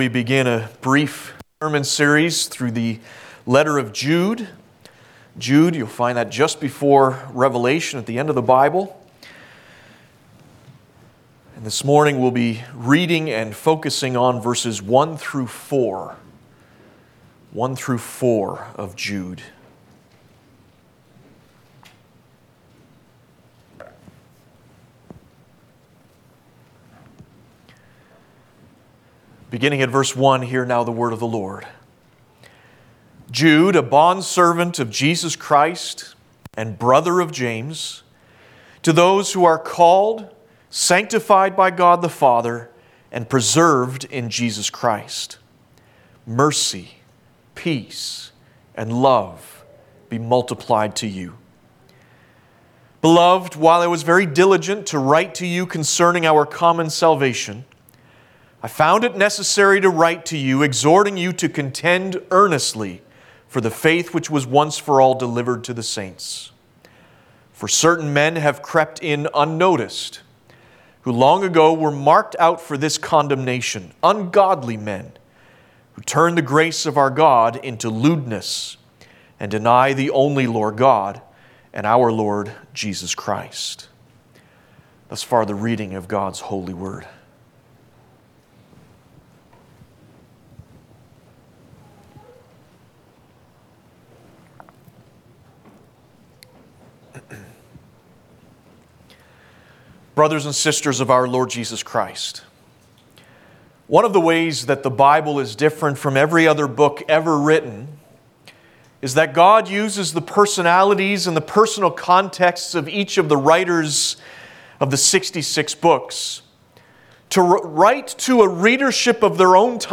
6.-Sermon_-Contend-Earnestly.mp3